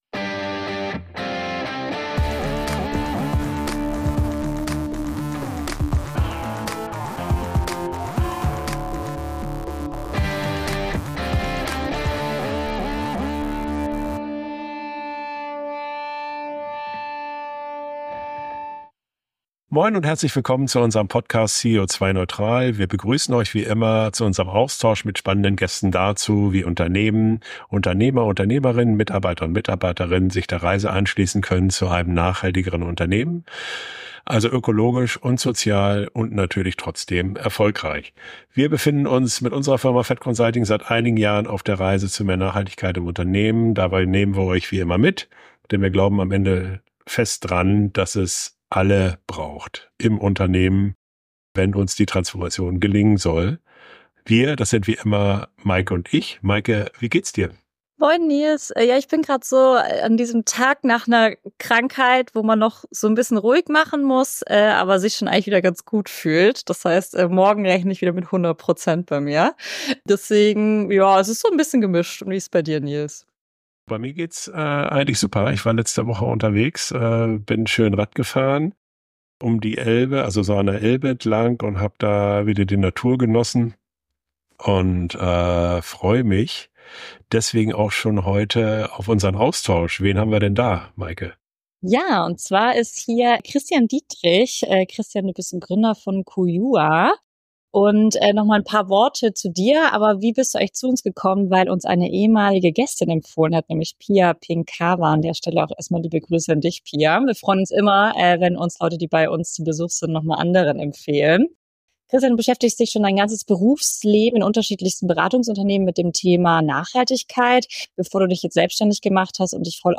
Der Interview-Podcast für mehr Nachhaltigkeit im Unternehmen Podcast